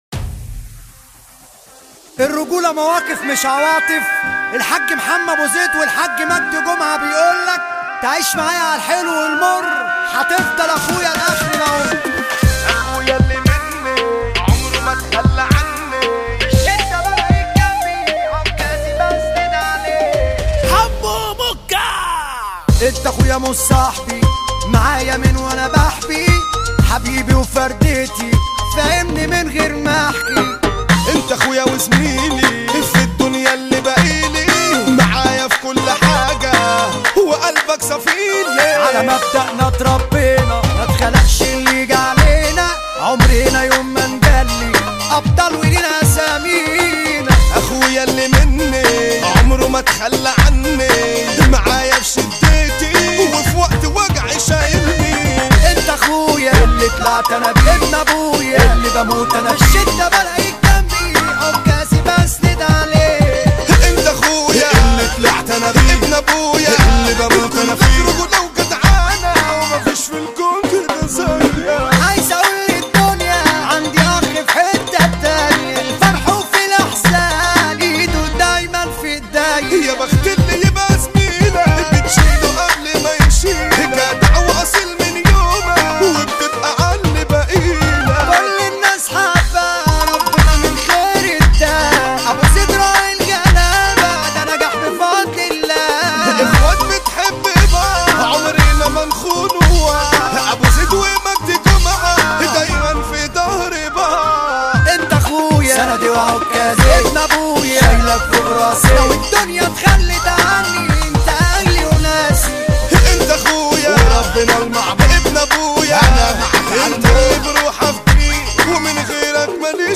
اغاني شعبي ومهرجانات